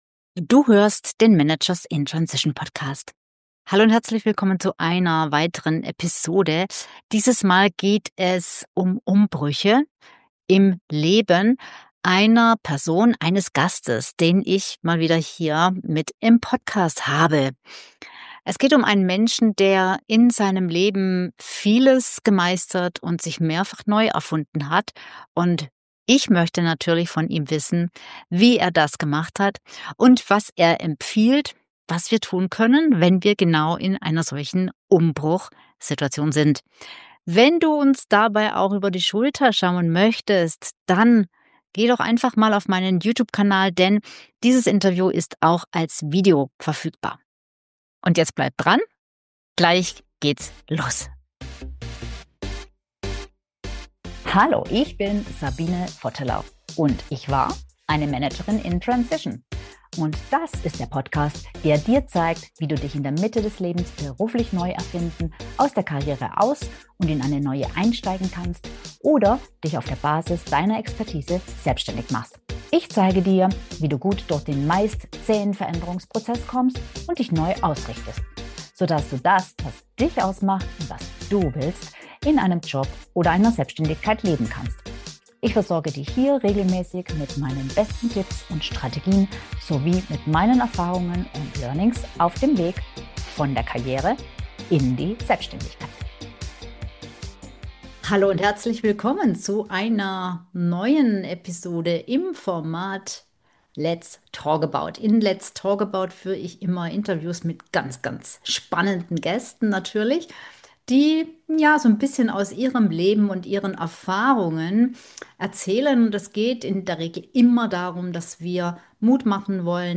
Wie du aus dem gedanklichen Loop rauskommst Warum Brüche zu Wendepunkten werden können Was wirklich hilft gegen Existenzangst Ein Gespräch über Zuversicht, Neuanfänge und die Kraft des Handelns.